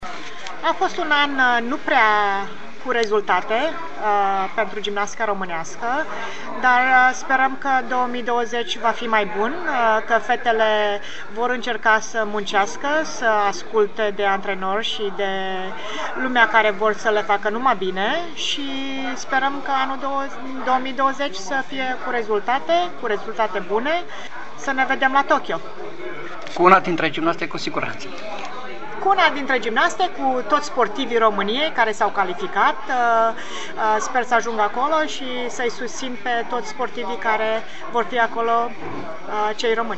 Stabilită în SUA din 1991, Daniela Silivaş (foto, în centru), una dintre cele mai mari gimnaste din istorie, s-a întors acasă, la Deva, la final de an și a acceptat să vorbească, pentru Radio Timișoara, despre ce a însemnat 2019 pentru gimnastica din România și despre cum crede vor arăta Jocurile Olimpice de la Tokyo, din acest an.